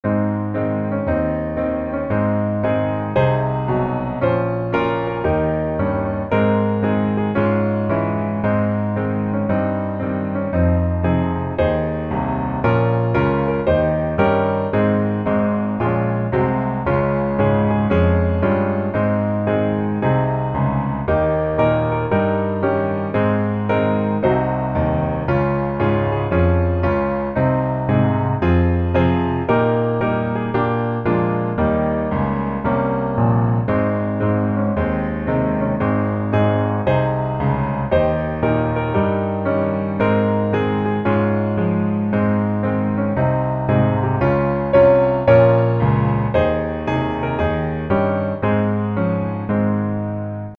Ab Majeur